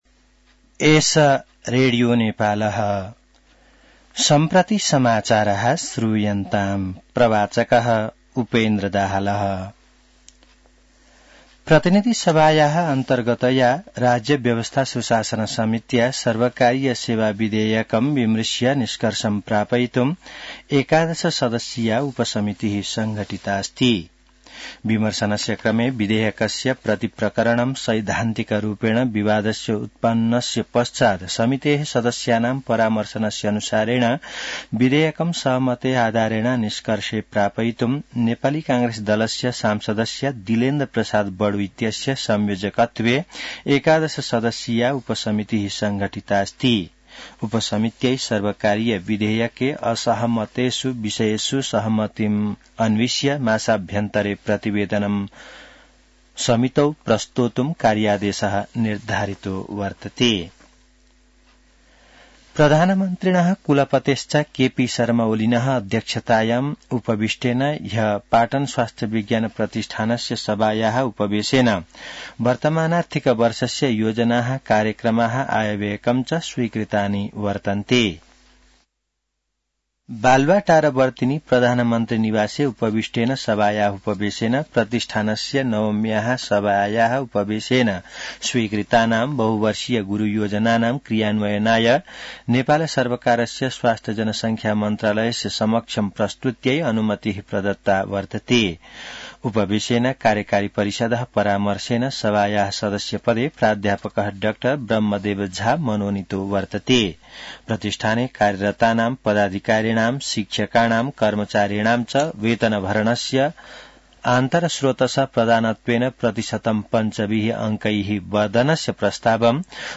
An online outlet of Nepal's national radio broadcaster
संस्कृत समाचार : ११ फागुन , २०८१